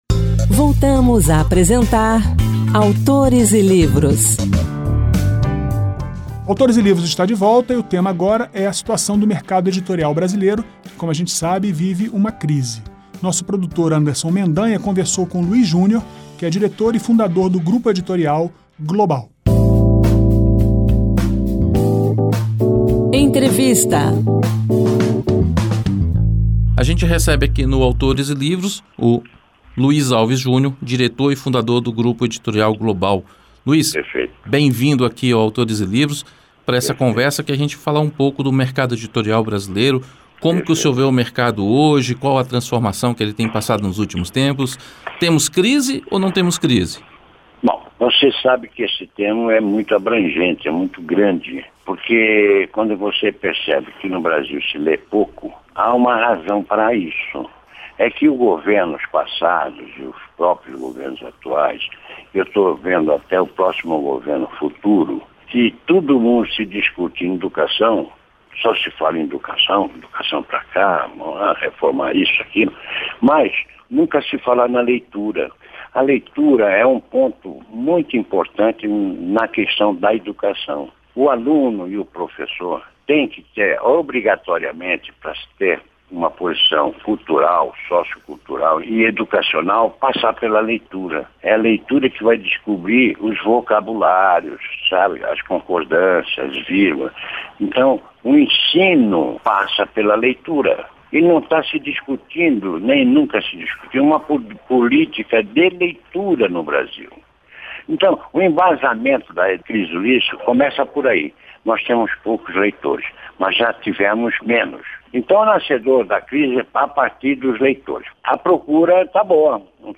A crise financeira que afetou redes de livrarias brasileiras é o tema da entrevista desta semana.